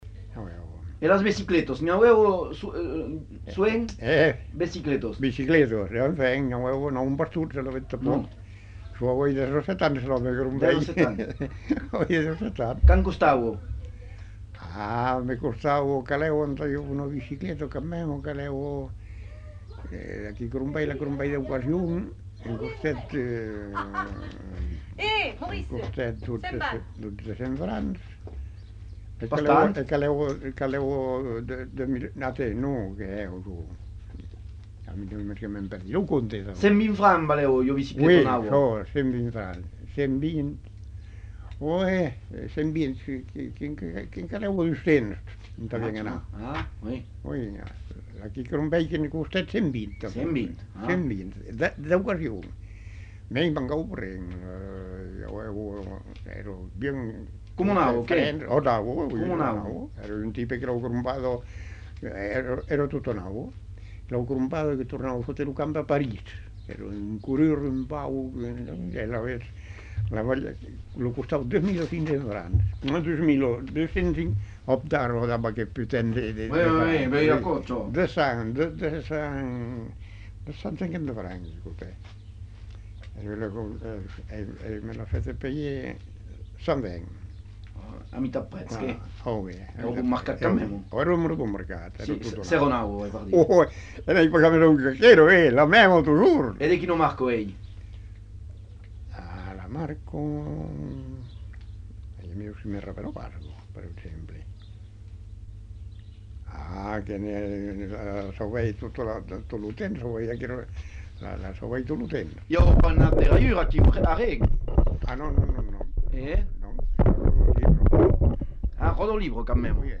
Aire culturelle : Savès
Lieu : Simorre
Genre : témoignage thématique